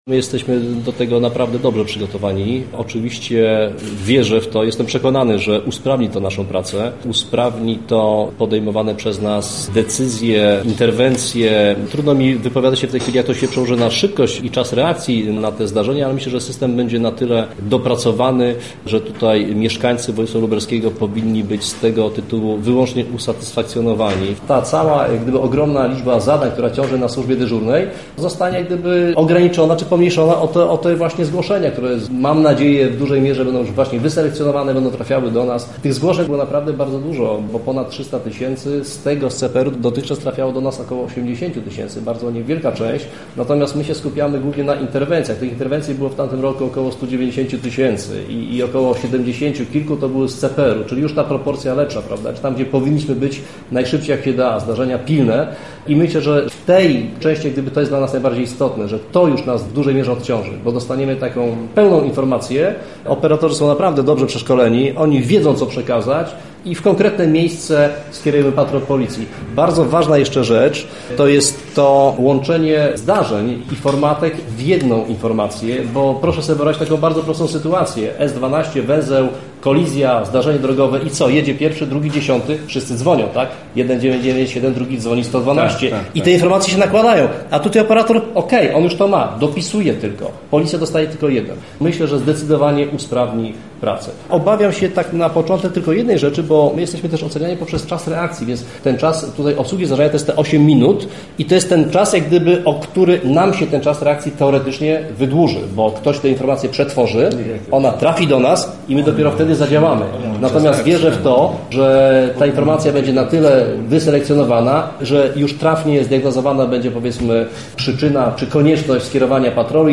Robert Szewc, Komendant Wojwódzkiej Policji w Lublinie wierzy, że cesja numeru 997 z CPR-em może skrócić ten czas.